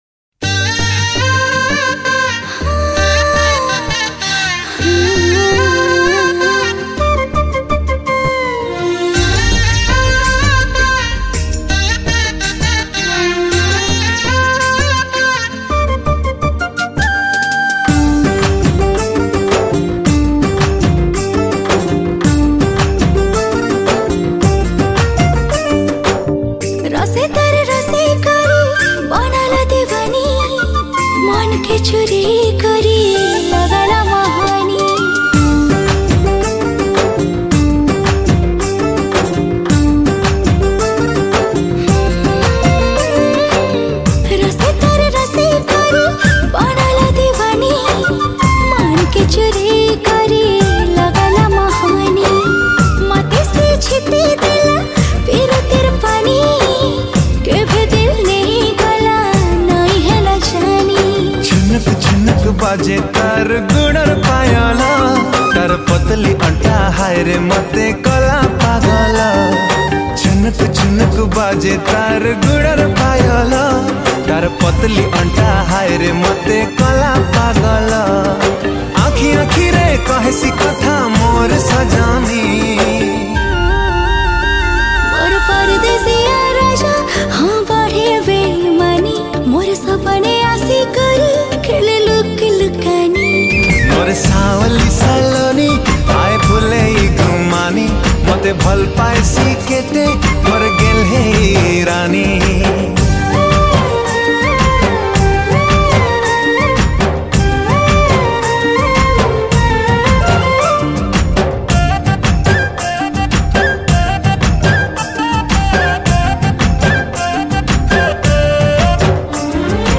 Sambalpuri Songs
Keyboard
Drums
Fluet